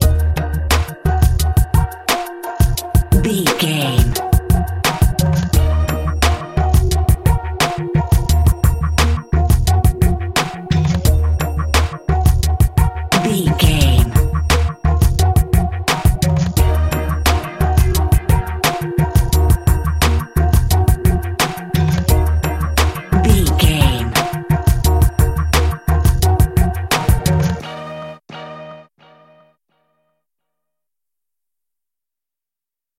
Aeolian/Minor
E♭
drum machine
synthesiser
electric guitar
funky
aggressive
hard hitting